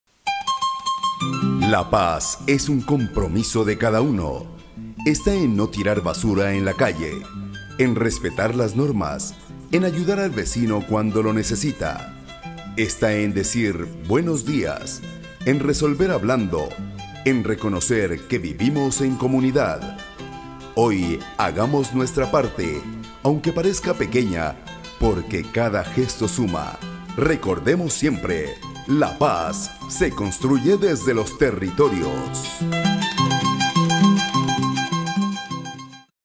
PERIFONEOS
Esta serie de audios recoge el perifoneo callejero como estrategia de comunicación territorial y comunitaria. Desde las calles, la voz amplificada informa, convoca y fortalece los vínculos sociales en el territorio.